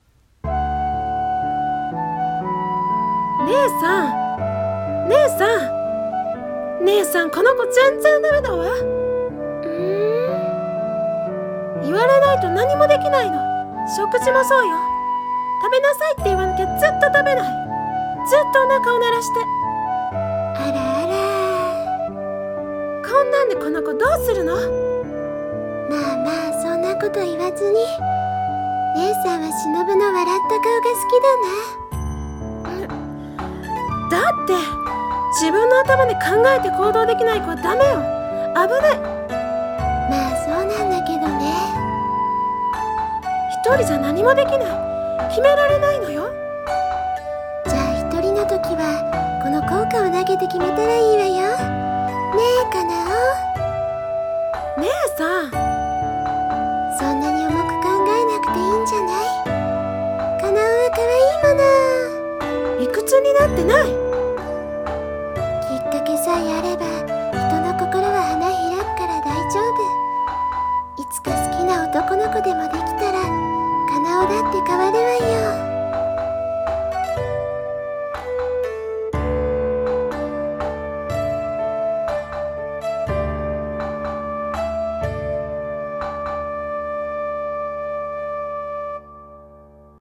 鬼滅の刃 胡蝶姉妹 声マネ 胡蝶カナエ 胡蝶しのぶ